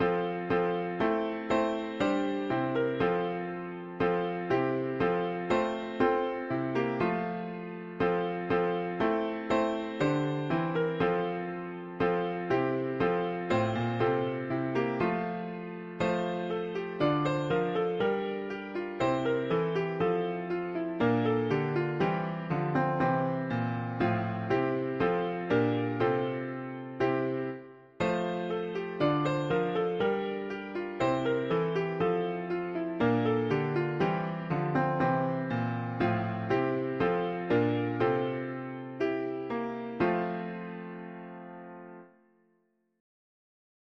Gloria in excelsis Deo, gl… english christian 4part winter
Traditional French carol
Key: F major